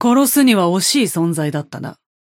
Yamato voice line - It's a shame to kill someone like you.